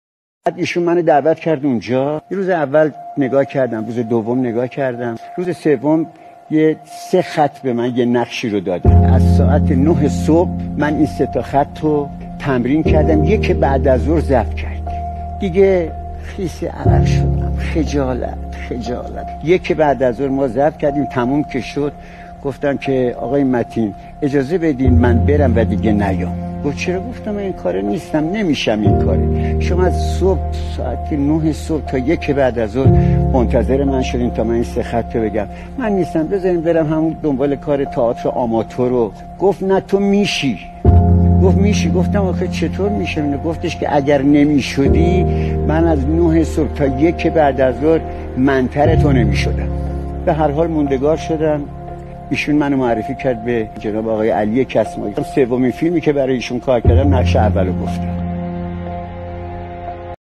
«منوچهر والی‌زاده» دوبلور باسابقه درگذشت +صدا